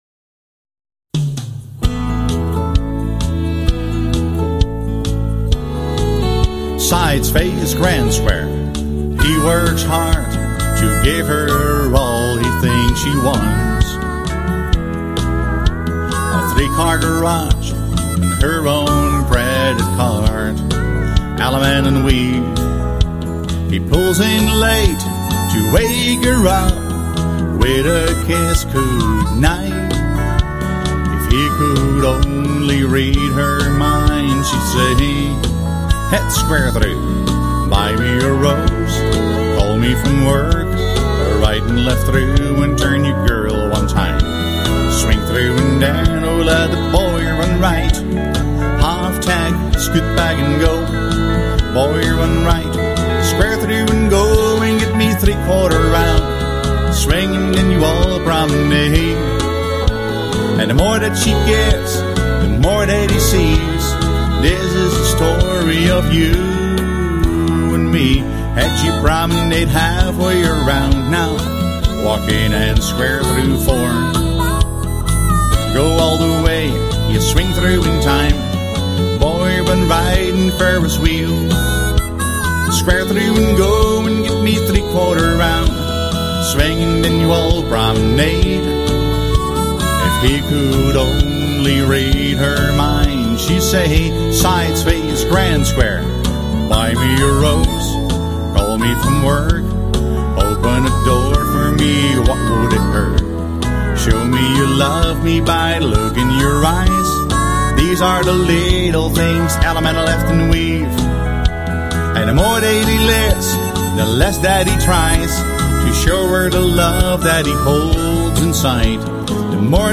Singing Calls Brand